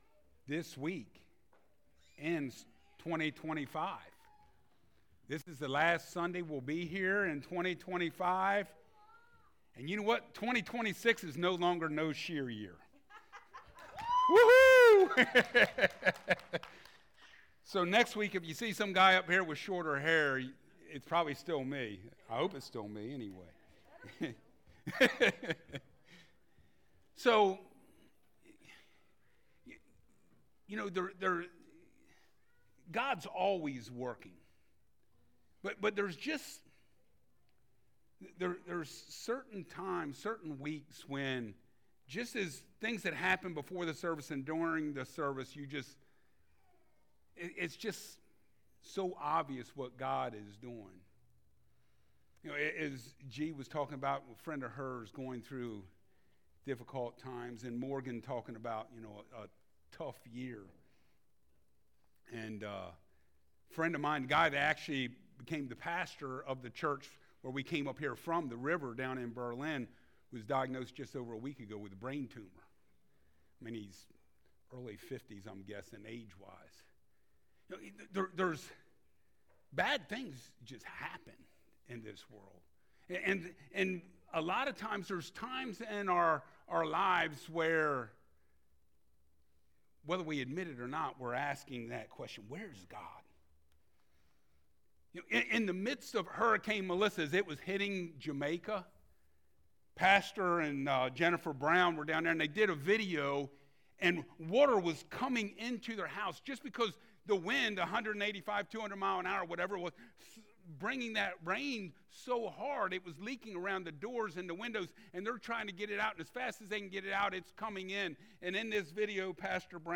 Passage: Isaiah 7:9b-17 Service Type: Sunday Mornings IMMANUEL